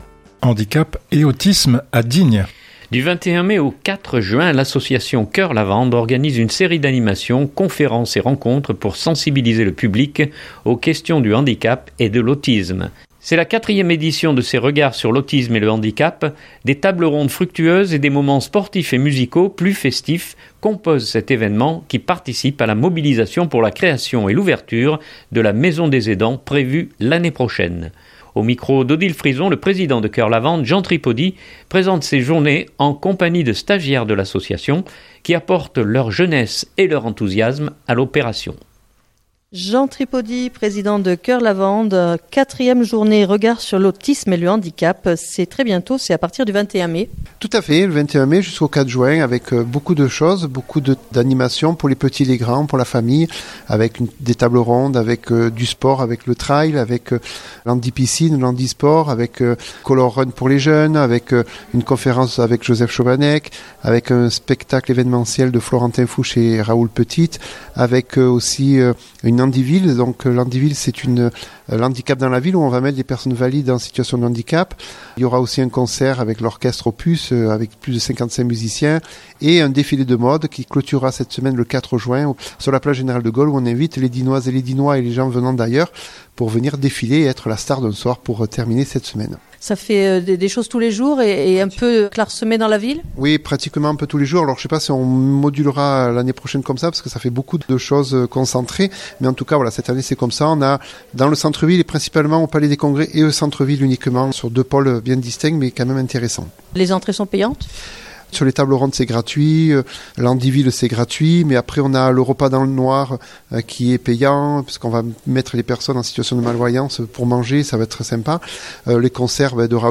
en compagnie de stagiaires de l’association qui apportent leur jeunesse et leur enthousiasme à l’opération